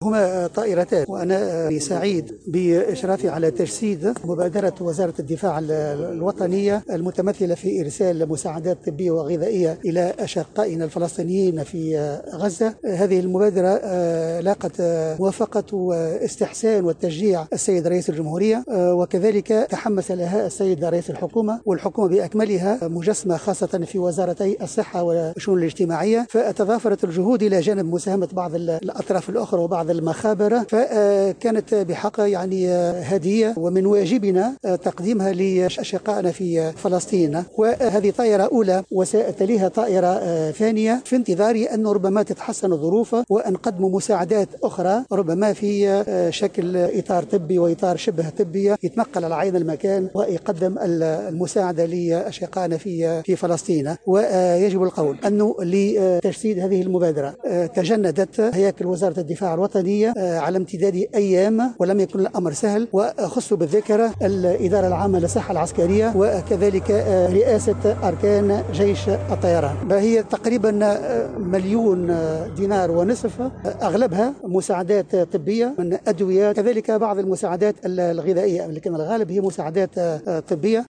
وأكد في تصريح لـ "الجوهرة اف ام" أنه سيتم ارسال طائرة مساعدات ثانية مرجحا ارسال فريق طبي الى غزّة لاحقا.